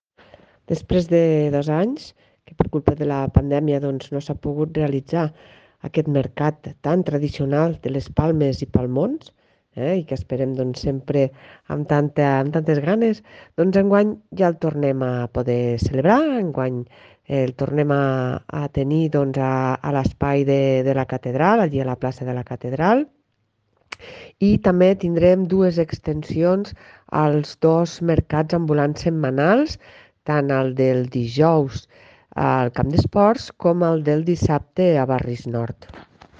Tall de veu de la regidora Marta Gispert
tall-de-veu-de-la-regidora-marta-gispert